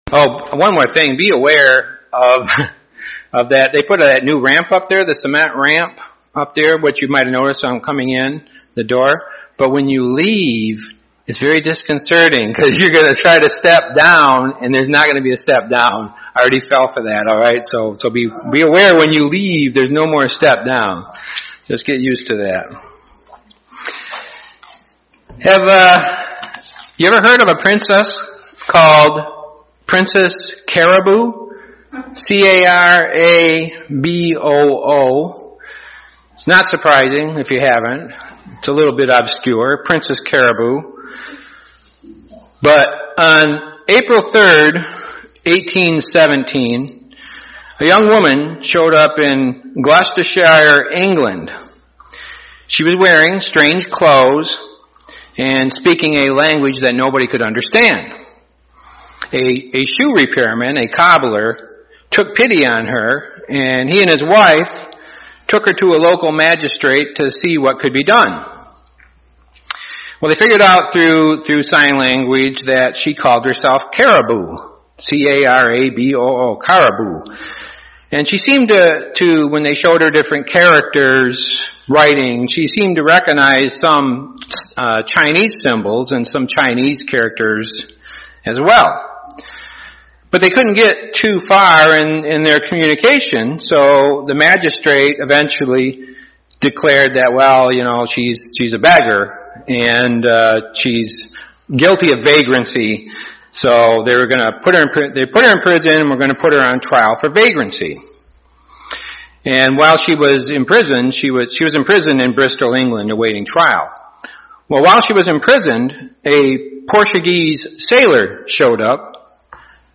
Given in Grand Rapids, MI Kalamazoo, MI